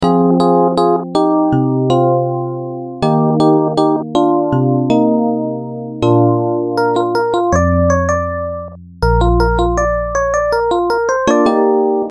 平滑的钢琴
描述：电钢琴，缓慢的节奏，让你平静下来
标签： 80 bpm Pop Loops Piano Loops 2.03 MB wav Key : Unknown
声道立体声